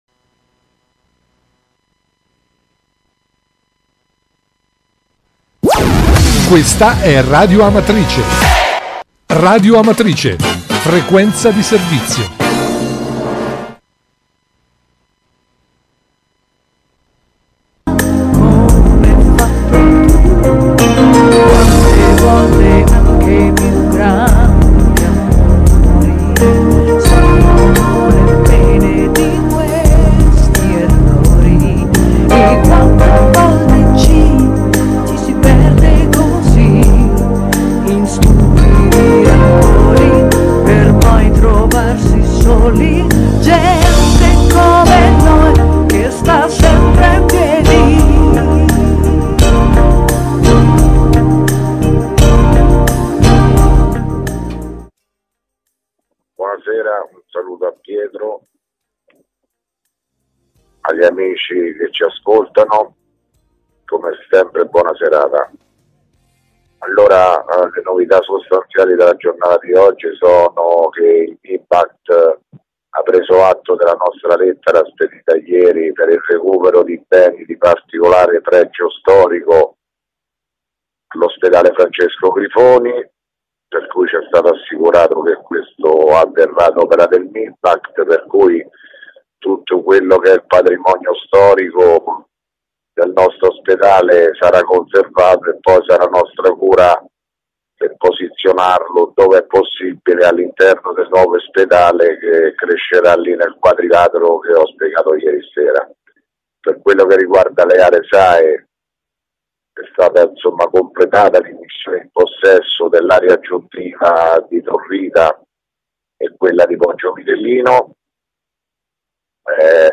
Di seguito il messaggio audio del Sindaco Sergio Pirozzi, del 17 febbraio 2017